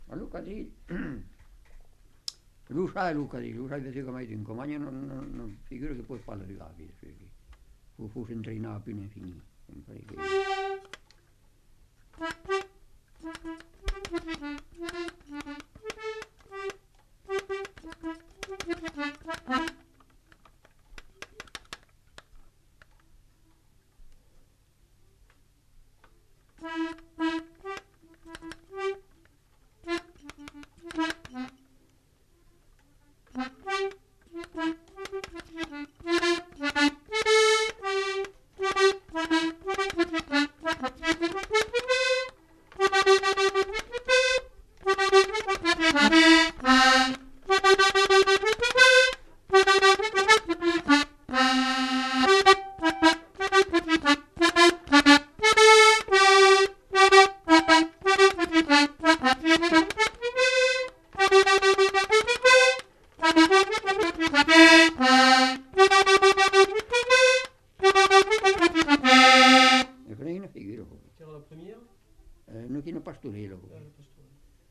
Genre : morceau instrumental
Instrument de musique : accordéon diatonique
Danse : quadrille
Notes consultables : L'informateur précise qu'il s'agit d'une pastourelle.